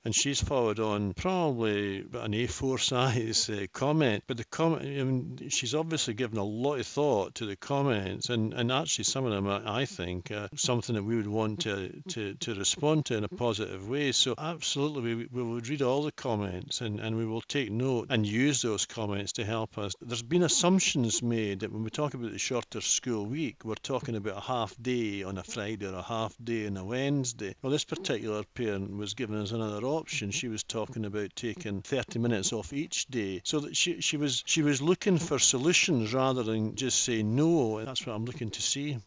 Bryan Poole, the local authority's education spokesman, says one parent's email in particular proves councillors will listen to better ideas: